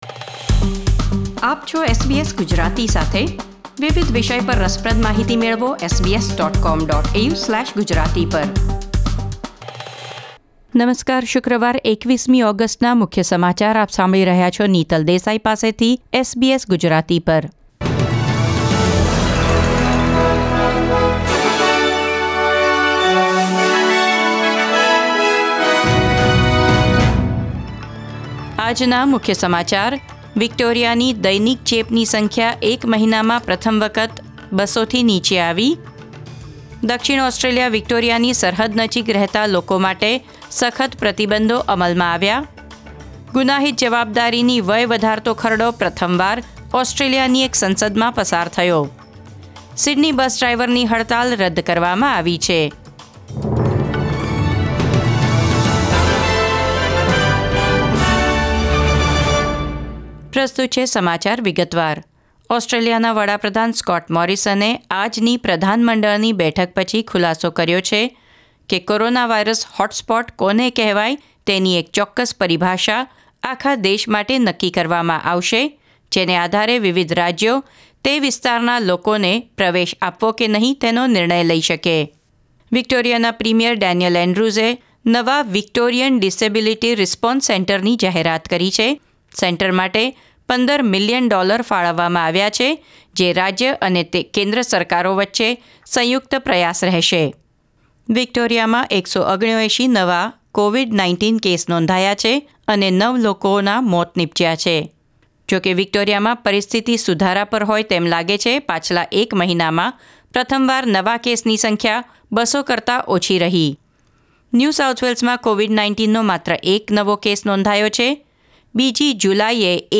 SBS Gujarati News Bulletin 21 August 2020